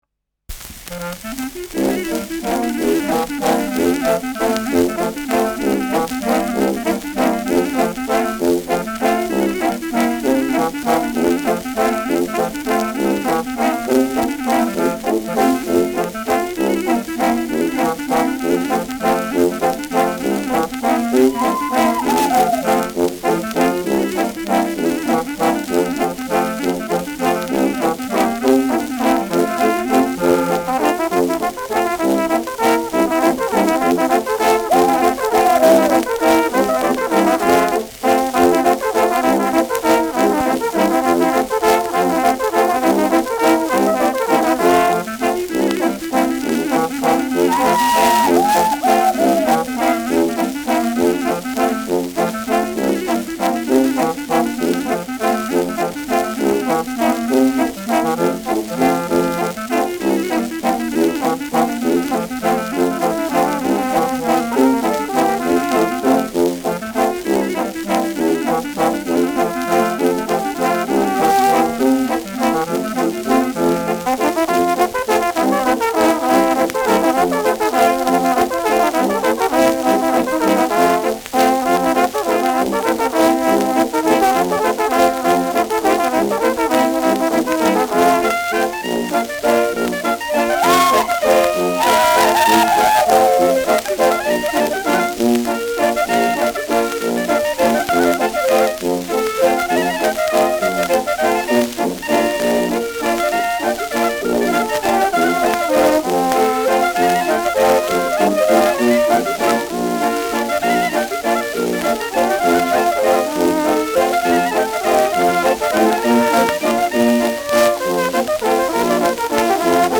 Schellackplatte
leichtes Rauschen : präsentes Knistern